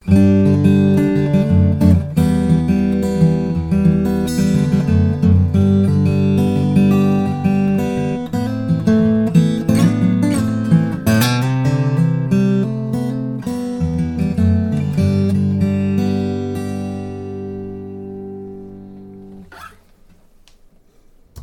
Sloped Shoulder Dreadnought Spruce/Bosse Cedar with sound
The Bosse Cedar looks and feels like Mahogany, but sound wise it resembles more like Rosewood.
If you like deep and warm, this is the way to go.